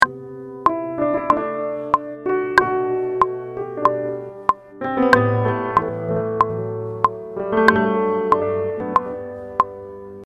Samplerで再現してみようと試みる 切り刻む位置を変えながら、 テンポに合わせてみると